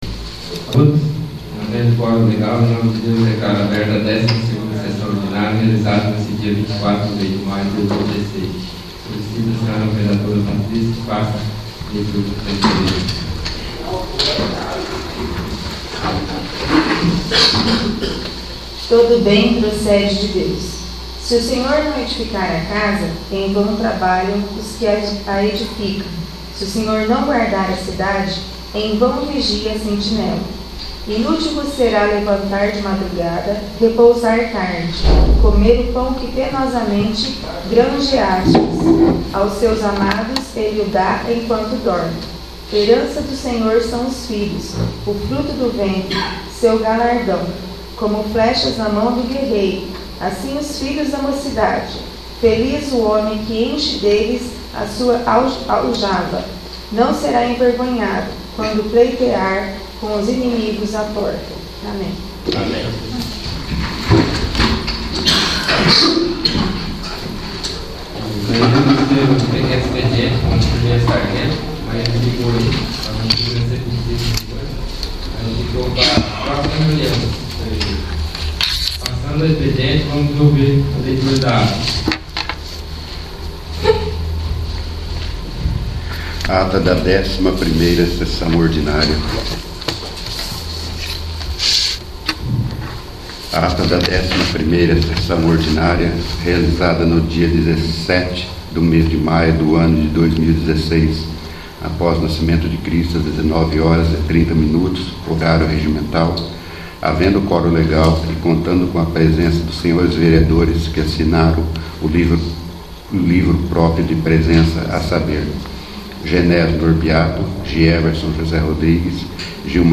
12º. Sessão Ordinária
| Ir para a navegação Ferramentas Pessoais Poder Legislativo Câmara de Vereadores do Município de Rio Bom - PR Mapa do Site Acessibilidade Contato VLibras Contraste Acessar Busca Busca Avançada…